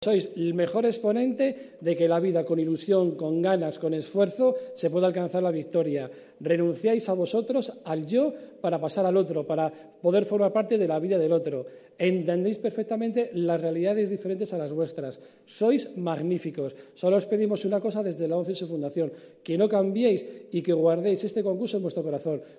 Como colofón, los escolares recibieron sus premios y diplomas en un acto que tuvo  lugar en la sede de la Fundación ONCE del Perro Guía en la cercana localidad de Boadilla del Monte.